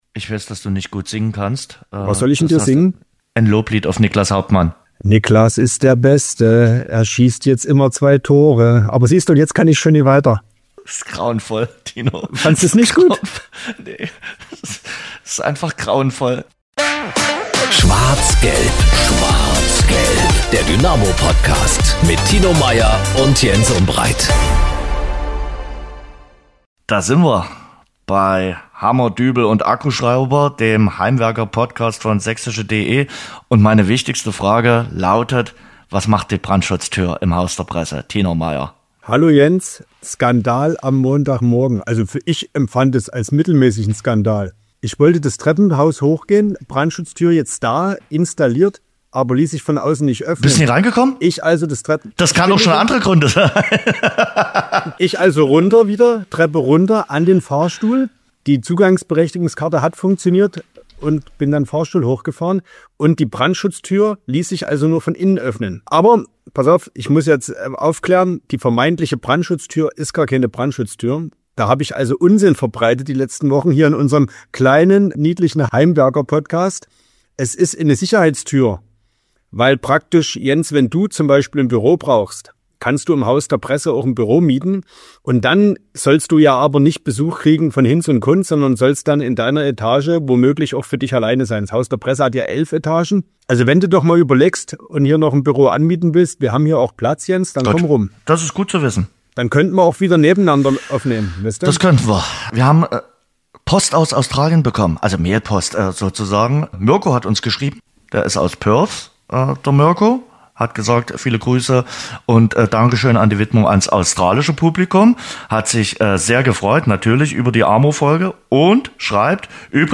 Im Mittelpunkt der Folge steht das Interview